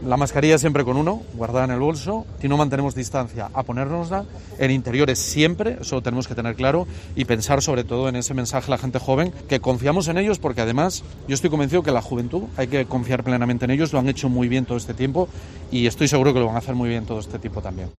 "Todos tenemos ganas de avanzar hacia la normalidad, pero invito a la prudencia", ha dicho el presidente del Principado al inicio de un acto, en Cantabria, para potenciar el Xacobeo junto a los presidentes cántabro, Miguel Ángel Revilla, y gallego, Alberto Núñez Feijoo.